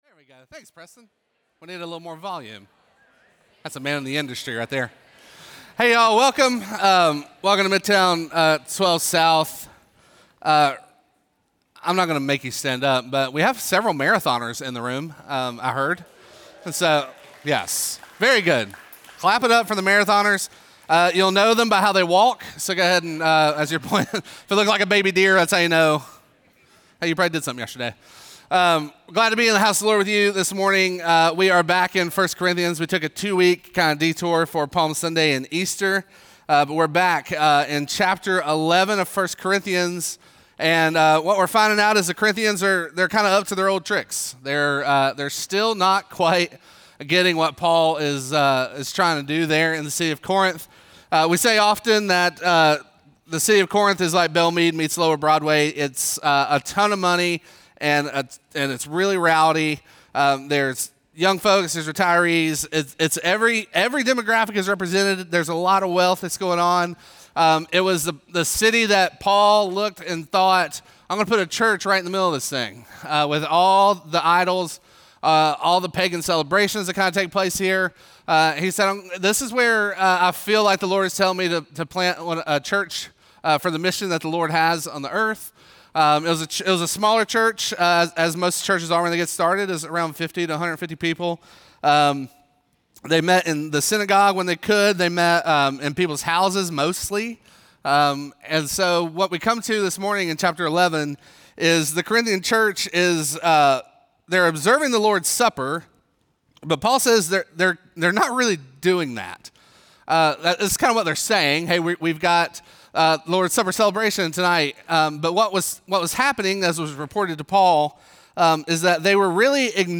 Midtown Fellowship 12 South Sermons The Lord’s Supper – WBE Apr 27 2025 | 00:30:27 1x 00:00 / 00:30:27 Subscribe Share Apple Podcasts Spotify Overcast RSS Feed Share Link Embed